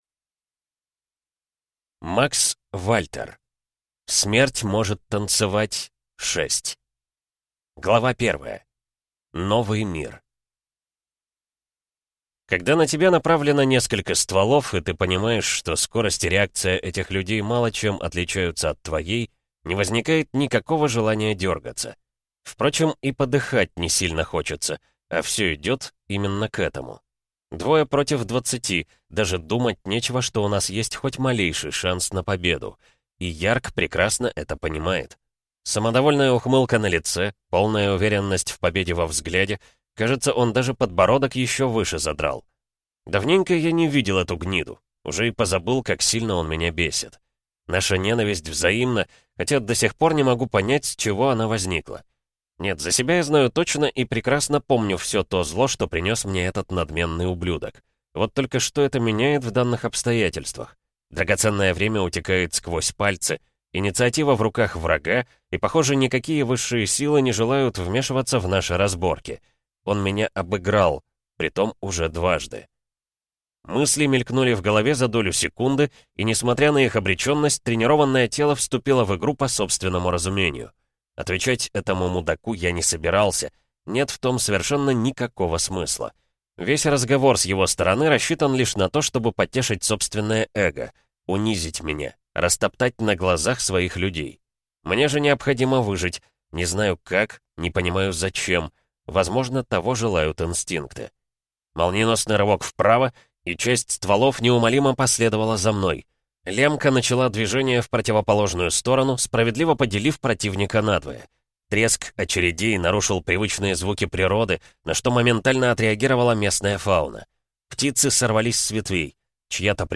Аудиокнига Смерть может танцевать 6 | Библиотека аудиокниг